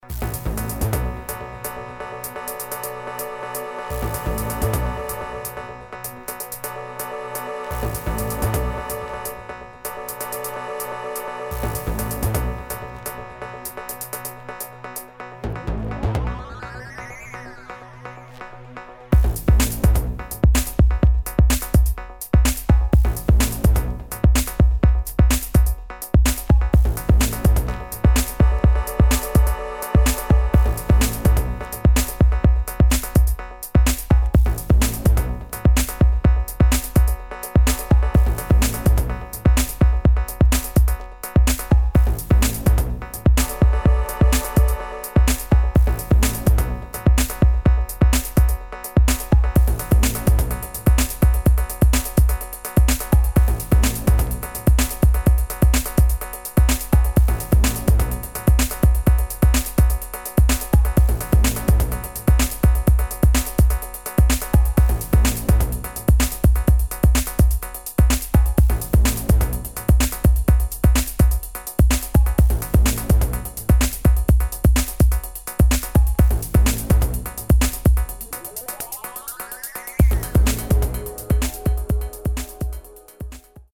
[ BASS / TECHNO ]